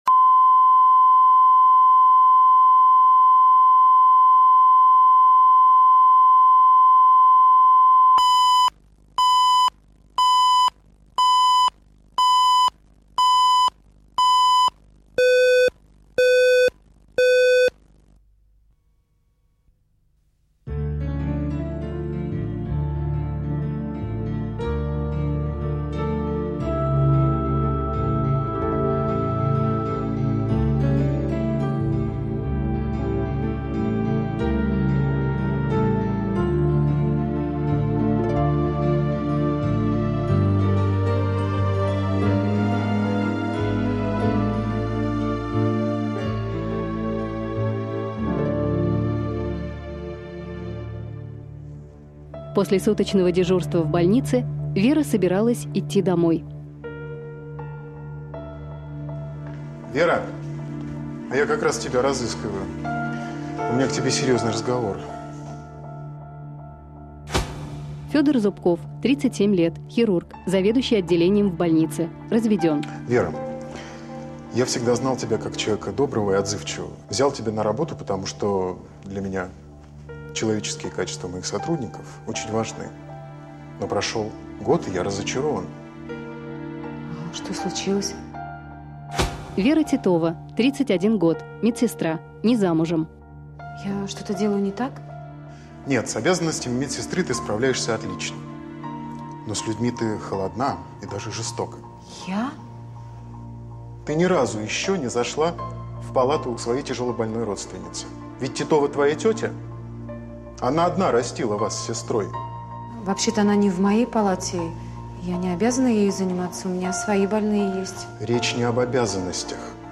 Аудиокнига Нелюбимая племянница | Библиотека аудиокниг
Прослушать и бесплатно скачать фрагмент аудиокниги